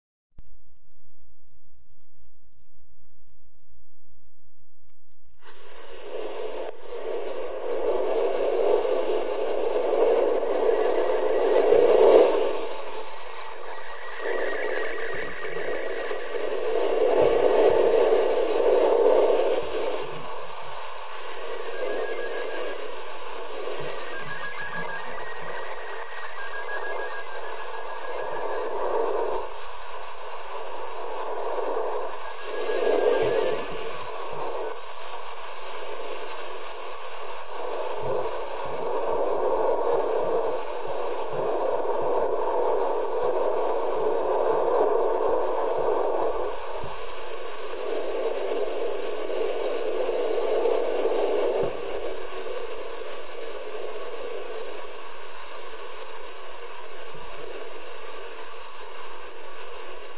The downlink picture will be transmitted using a set of audio tones, similar to a computer modem, using a ham radio picture standard called Slow-Scan Television (SSTV).
This standard sends the entire image in 36 seconds.
SSTV Audio (MP3)
Using 2m boomer right polarized 12 elements